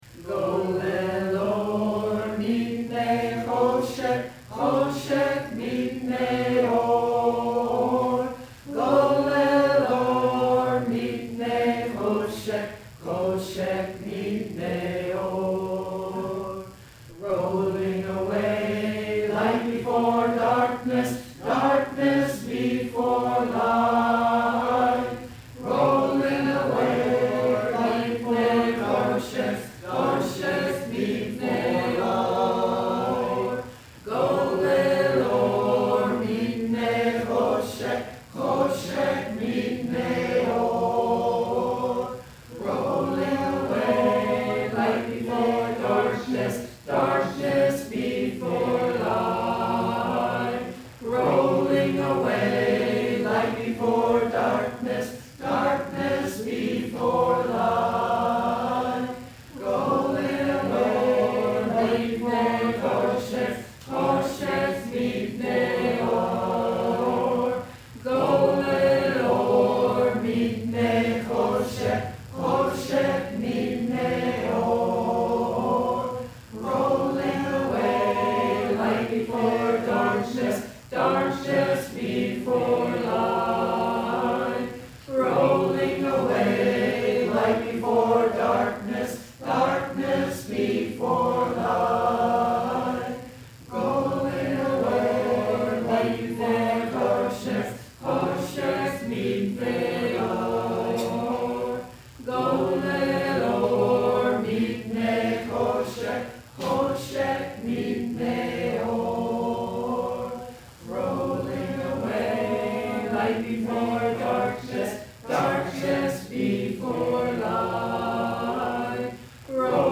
in several Hebrew chants during a Sunday morning worship service on February 17, 2008.*
*All chants were recorded live at Mill Valley Community Church, Mill Valley, California, February 17, 2008.